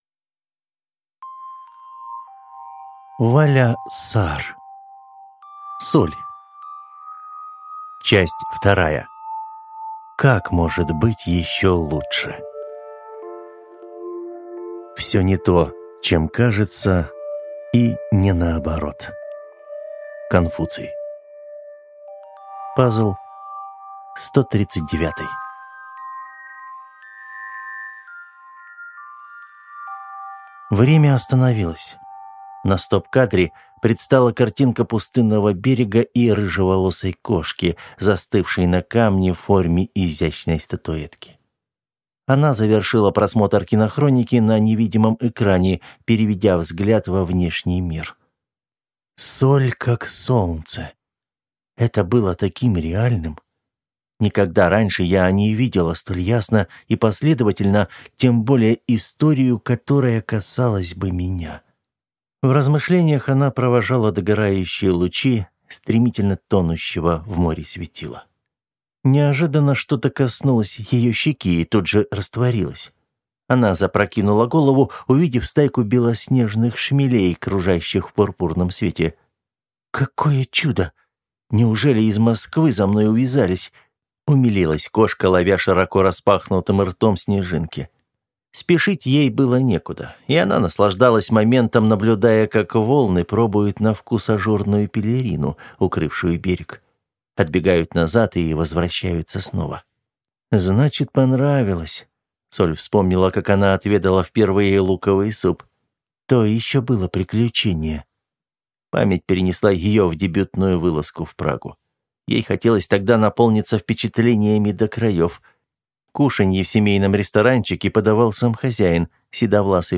Аудиокнига Соль. Часть вторая. Как может быть ещё лучше?